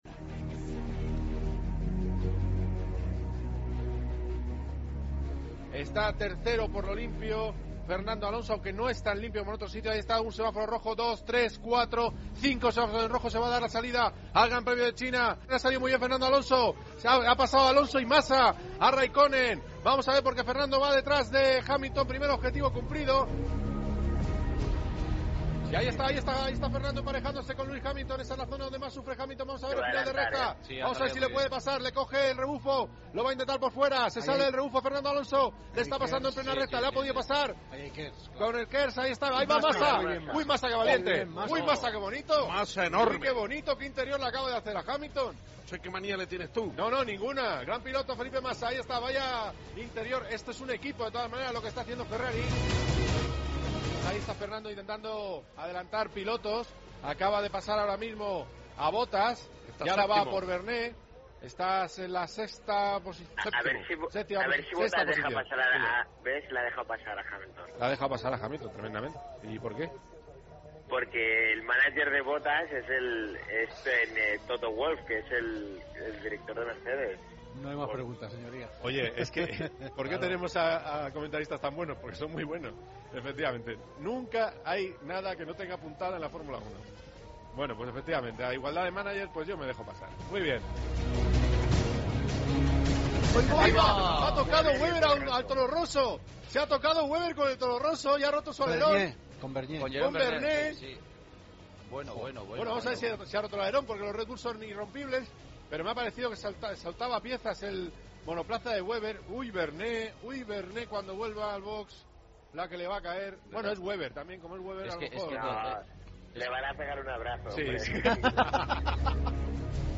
Resumen de la retransmisión del Gran Premio de china